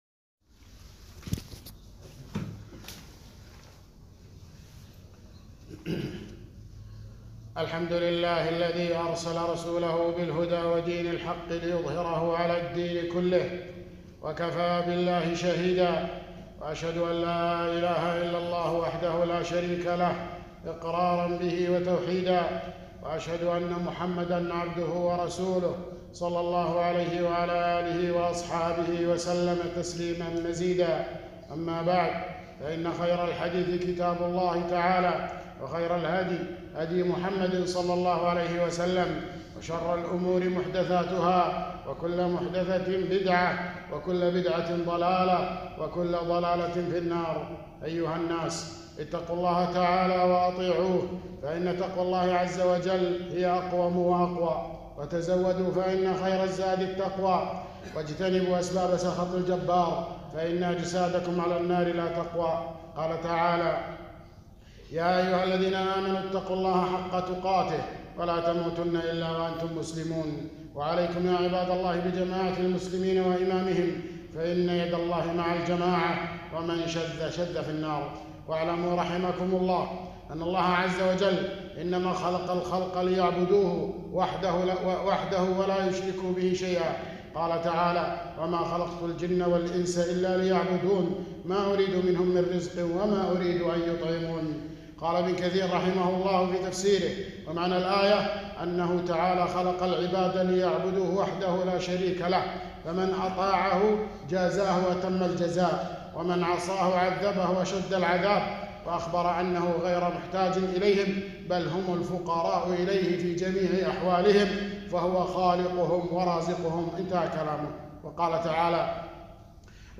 خطبة - الرد على من أجاز طلب المدد من الأموات - دروس الكويت